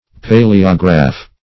Paleograph \Pa"le*o*graph\, n. An ancient manuscript.